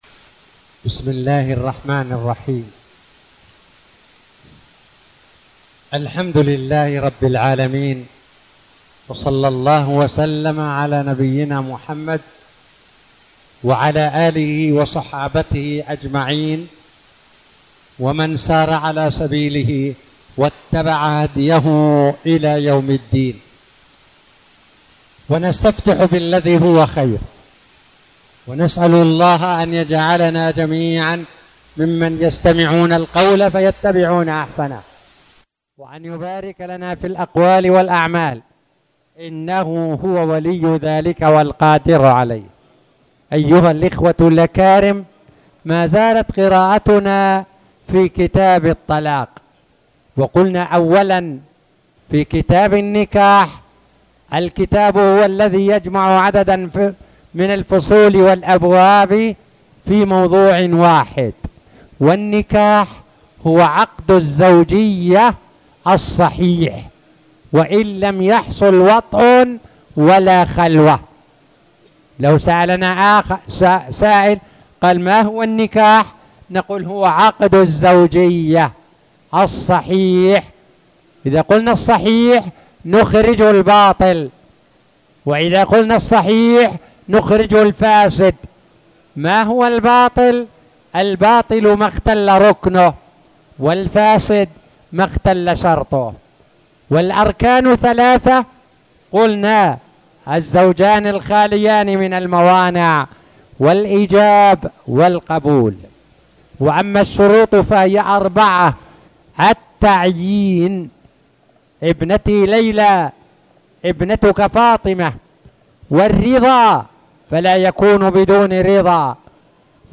تاريخ النشر ١٩ رجب ١٤٣٨ هـ المكان: المسجد الحرام الشيخ